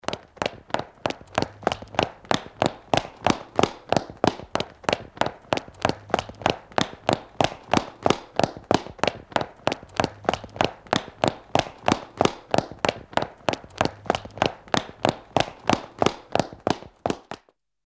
急促的高跟鞋2.mp3
通用动作/01人物/01移动状态/高跟鞋/急促的高跟鞋2.mp3
• 声道 立體聲 (2ch)